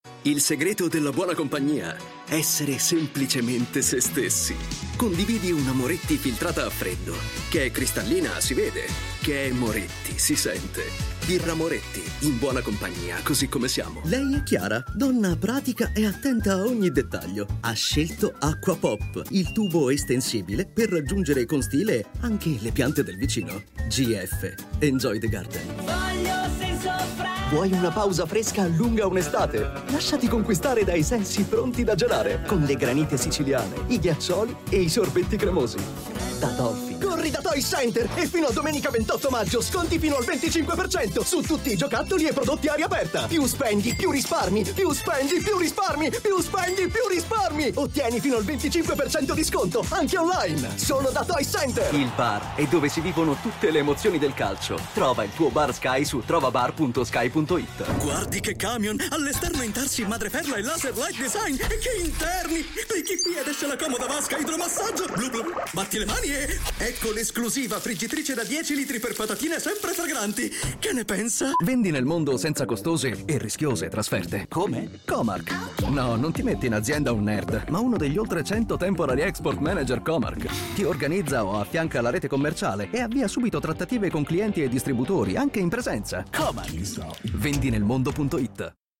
Male
Confident, Corporate, Energetic, Engaging, Friendly, Funny, Sarcastic, Streetwise, Upbeat, Versatile, Warm
CORPORATE-PODCAST.mp3
Microphone: Neumann TLM-103